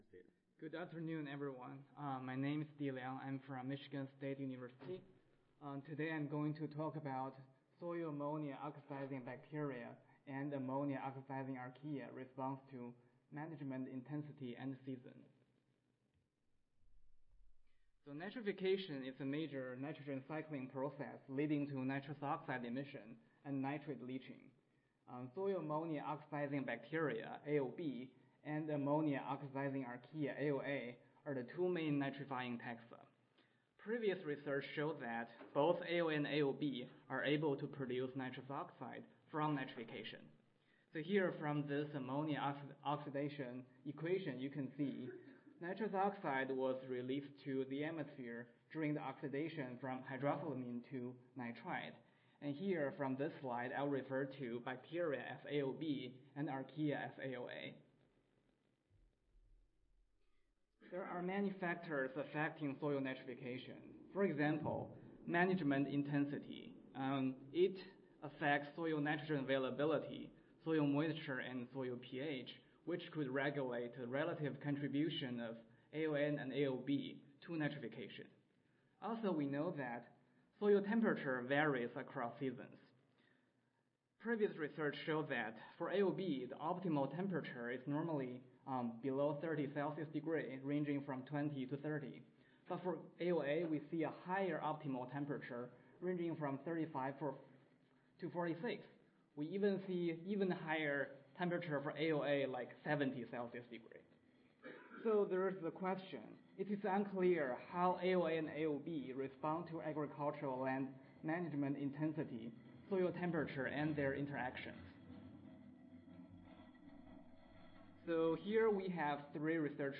Michigan State University Audio File Recorded Presentation